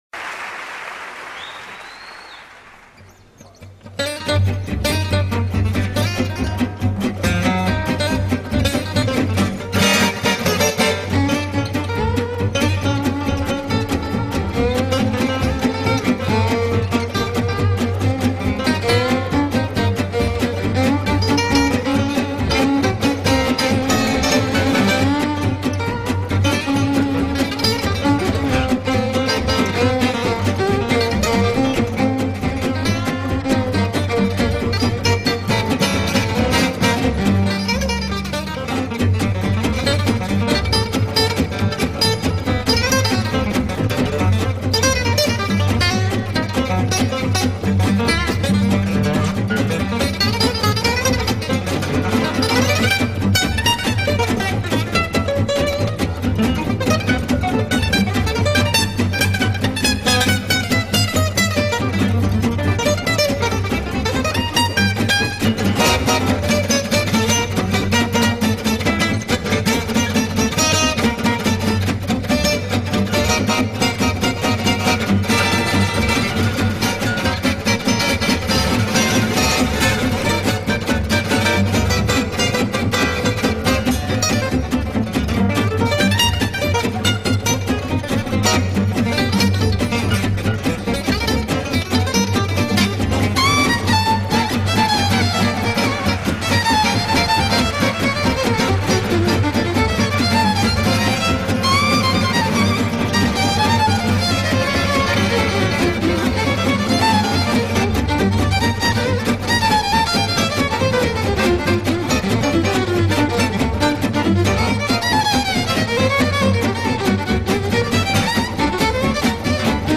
Gipsy jazz